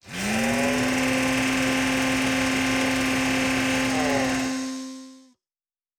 pgs/Assets/Audio/Sci-Fi Sounds/Mechanical/Servo Big 10_2.wav at 7452e70b8c5ad2f7daae623e1a952eb18c9caab4
Servo Big 10_2.wav